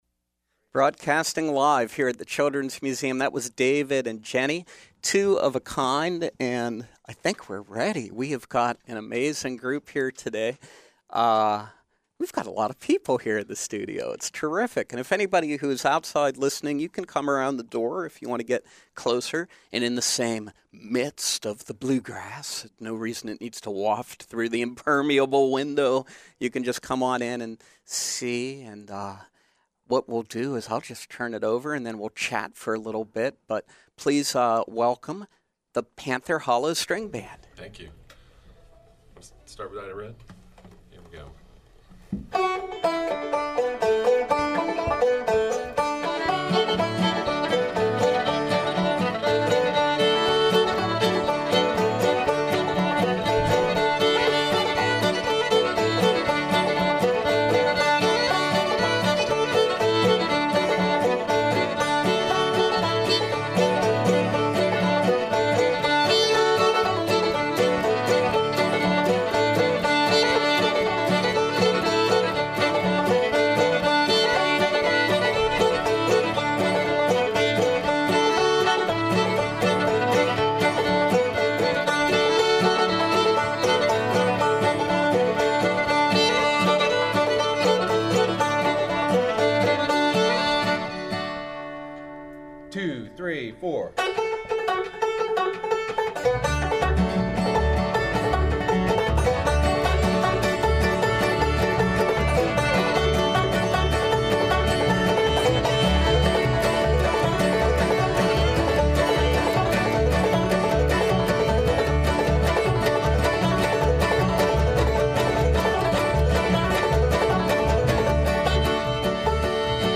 a fine Appalachian string band